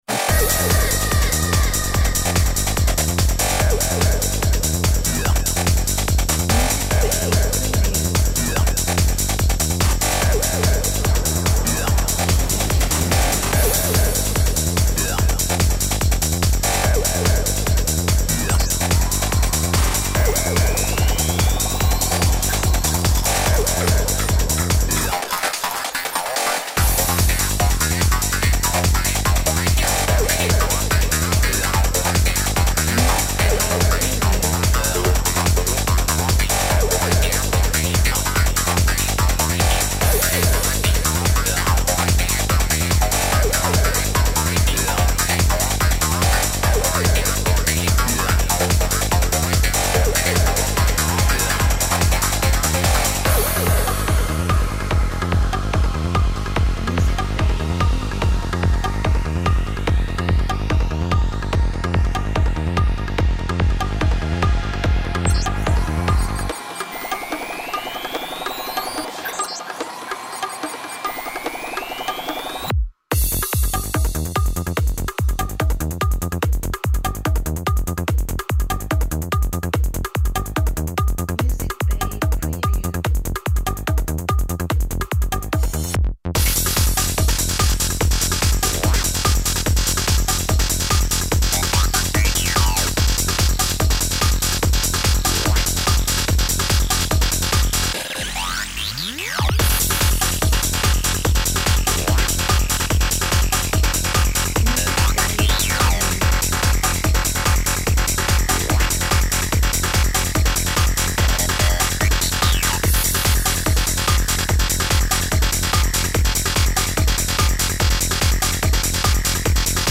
Electro music for modern projects